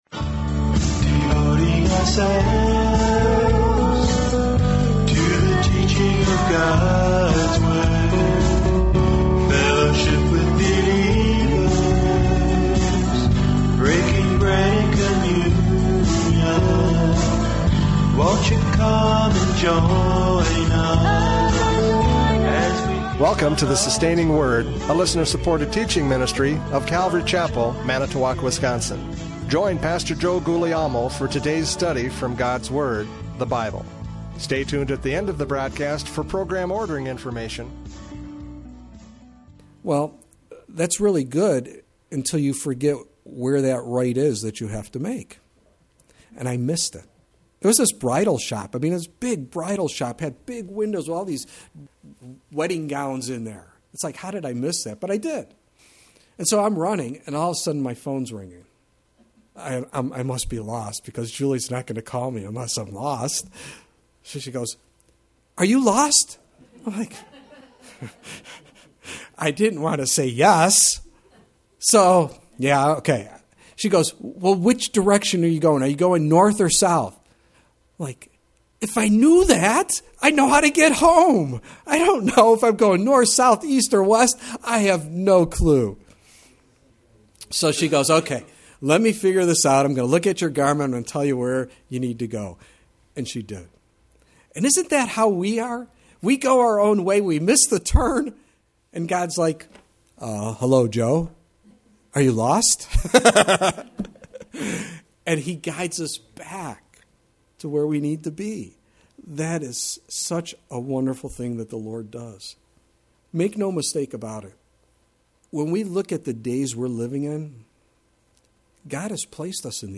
Judges 5:6-8 Service Type: Radio Programs « Judges 5:6-8 Life Under Oppression!